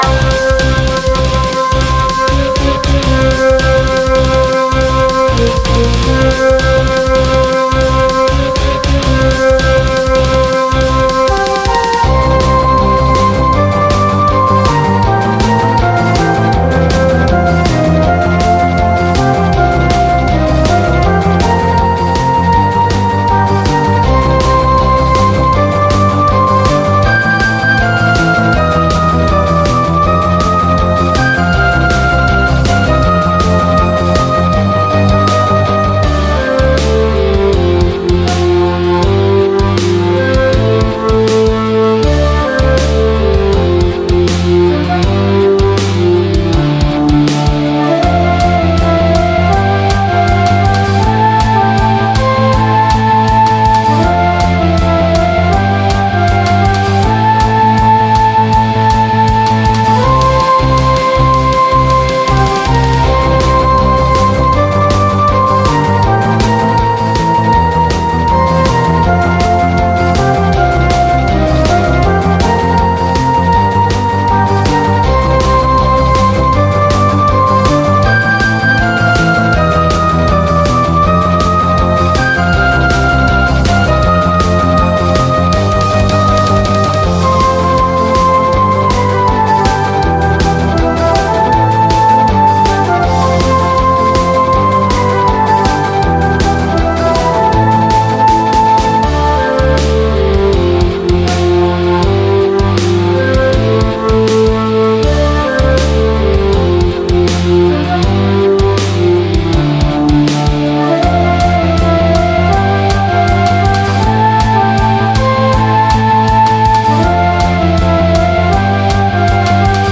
ジャンル：和風ロック × ドラマチック
ギターリフとドラムによる激しいイントロから始まり、和楽器「尺八」の情緒的なメロディが展開を彩ります。
ロックの力強さと和風の哀愁を掛け合わせた、ドラマチックなBGMです。
激しい戦いの幕引きを思わせる力強いイントロから、一転して哀愁漂う尺八がメインメロディを奏でます。
• イントロ：ディストーションギター＋ドラム（8分刻みの鋭いリフ）
• メインメロ：尺八（生風なサンプル音源を使用）
• バック：ギターリフ継続＋ロックなドラムパーカッション、低音のベースが支える構成
フリーBGM ロック 和風 切ない 感動 戦闘後 エンディング ドラマチック バトル 尺八